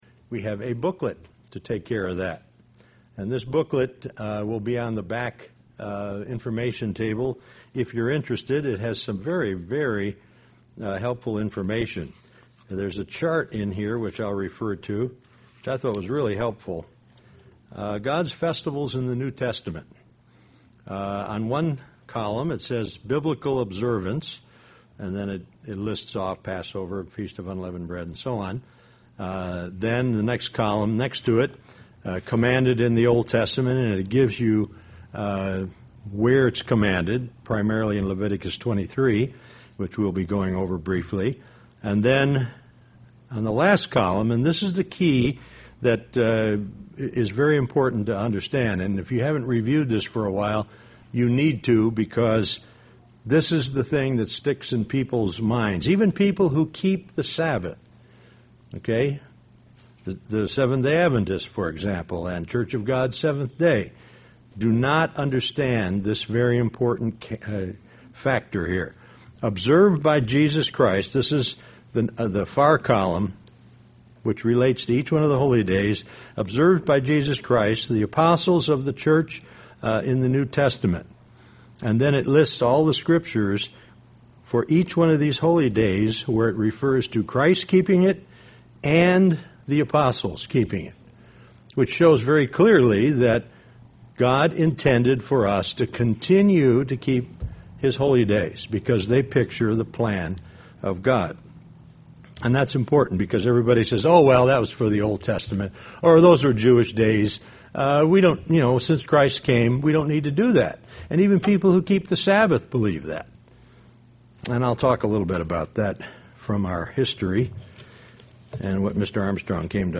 Given in Beloit, WI
UCG Sermon Studying the bible?